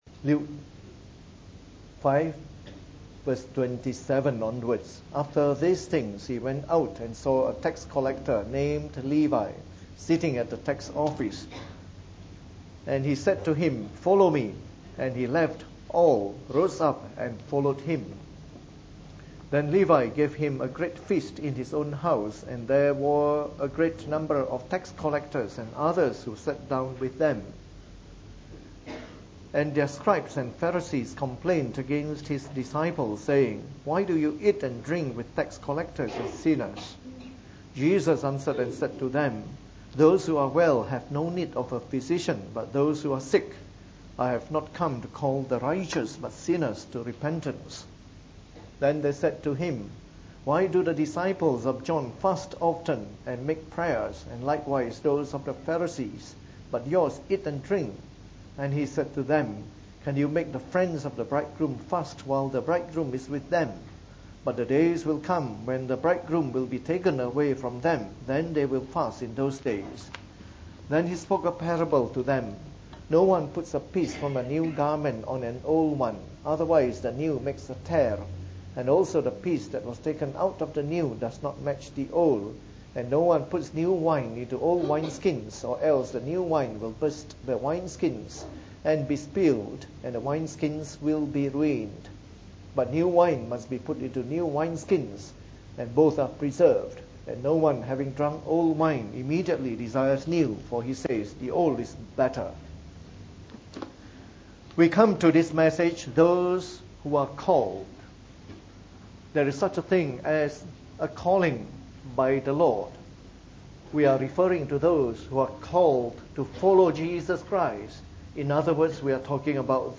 From our series on the “Gospel According to Luke” delivered in the Evening Service.